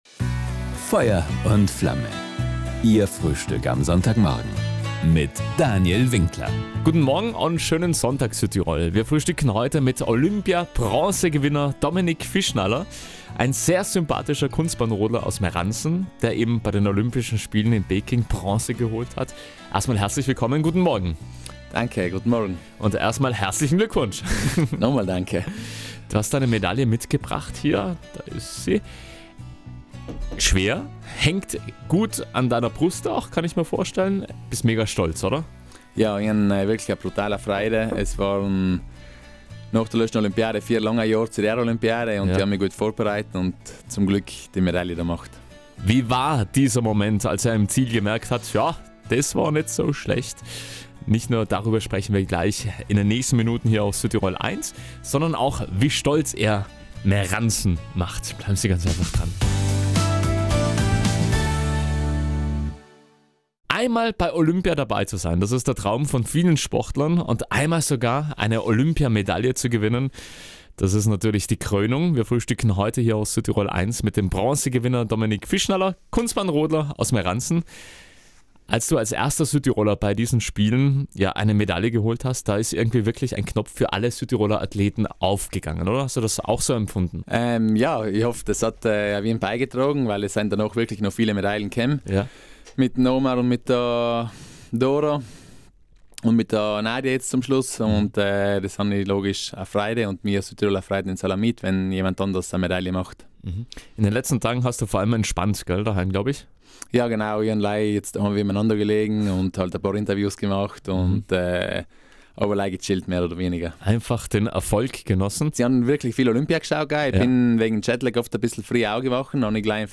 Ein Ausnahmekönner aus Meransen, der vor kurzem bei den Olympischen Winterspielen in Peking seine erste Olympia-Bronzemedaille geholt hat. Wir lassen den sympathischen Eisacktaler diesmal im Sonntagsfrühstück auf Südtirol 1 hochleben.